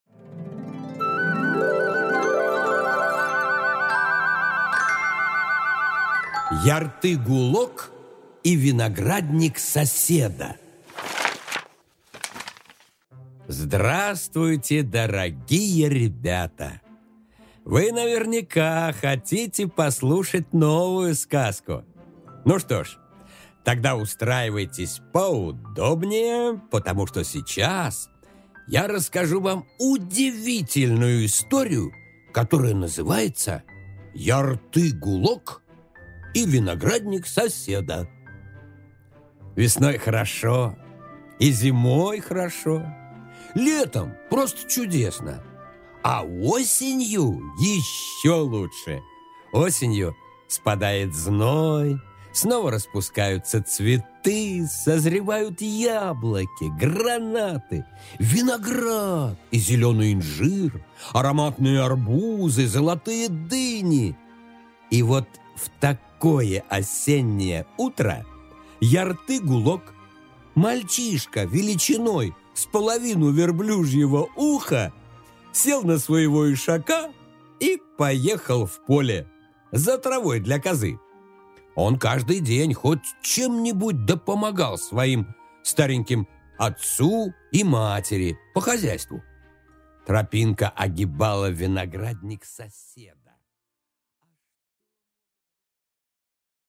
Аудиокнига Ярты-гулок и виноградник соседа | Библиотека аудиокниг